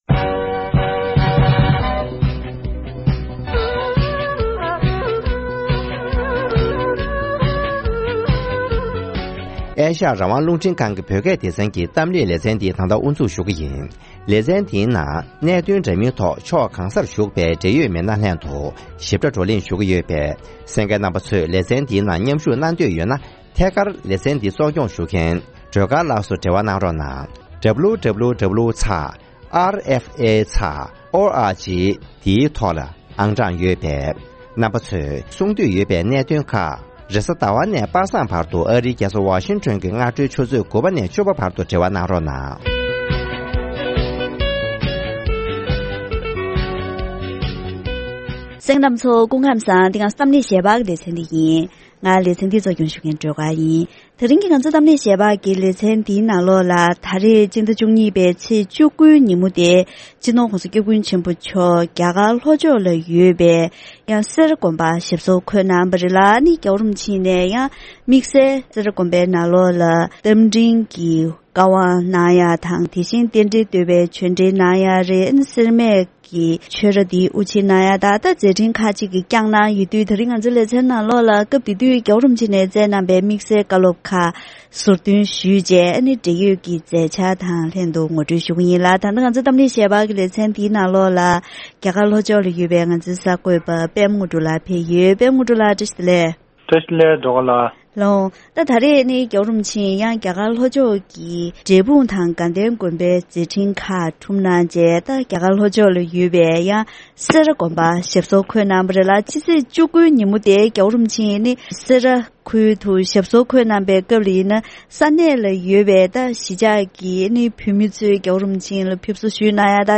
༧གོང་ས་མཆོག་ནས་རྒྱ་གར་ལྷོ་ཕྱོགས་སེ་ར་དགོན་པར་དད་ལྡན་མང་ཚོགས་ལ་རྟ་མགྲིན་གྱི་བཀའ་དབང་དང་སེར་སྨད་ཆོས་རྭ་དབུ་འབྱེད་མཛད་སྐབས་ཀྱི་བཀའ་སློབ་ཁག་ཕྱོགས་སྒྲིག་ཞུས་པ།